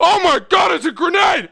ALLVOICES
1 channel
WELDER-GERNADE2.mp3